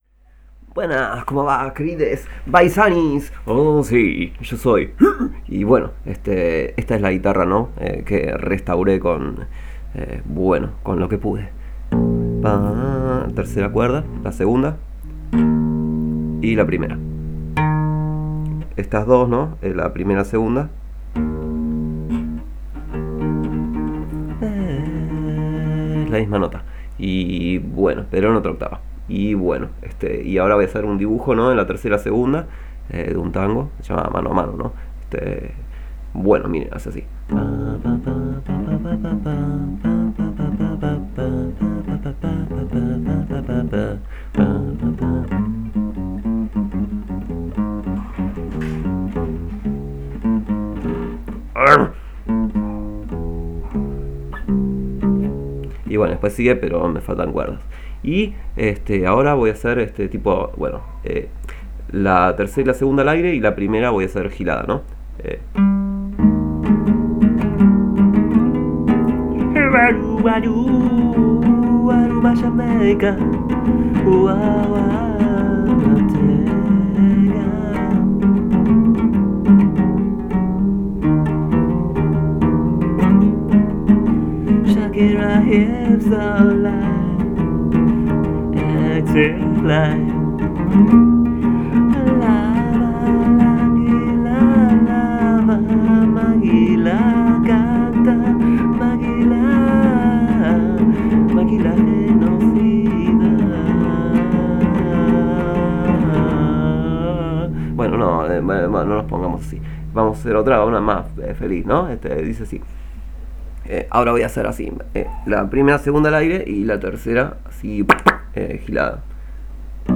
Acá el audio de cómo suena la guitarra restaurada, amigues baisanis.